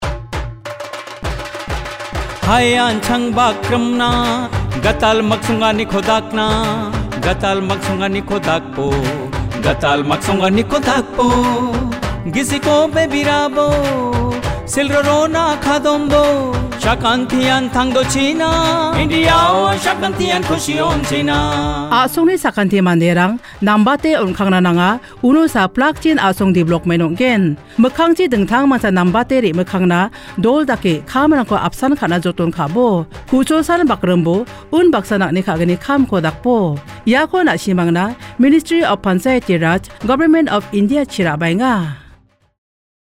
56 Fundamental Duty 10th Fundamental Duty Strive for excellence Radio Jingle Garo